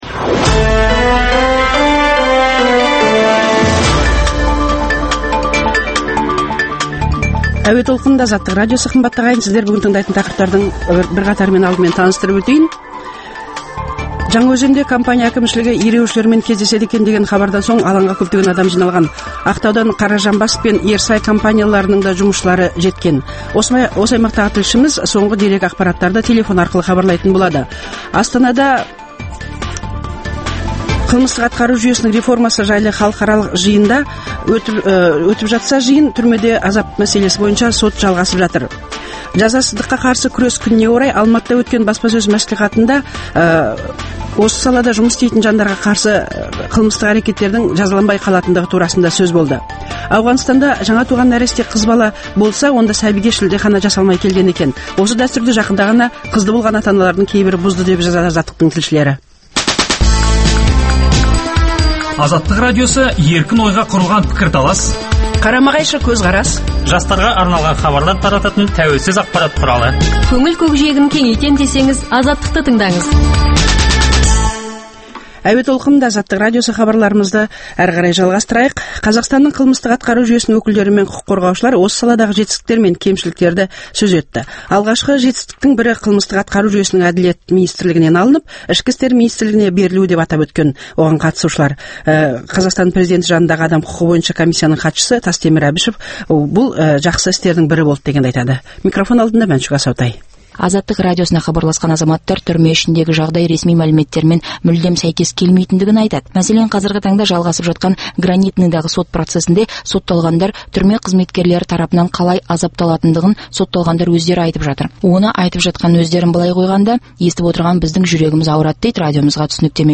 Осы аймақтағы тілшіміз соңғы ақпарат-деректерді телефон арқылы хабарлайды.